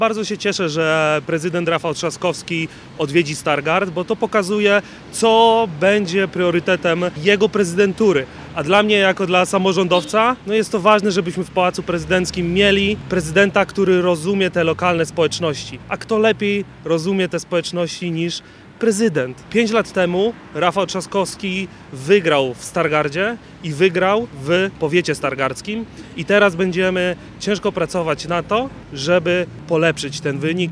Radny Rady Miejskiej Dawid Pycz wyjaśnia, dlaczego z perspektywy samorządowca wybór Rafała Trzaskowskiego ma kluczowe znaczenie.